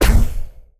etfx_explosion_poof.wav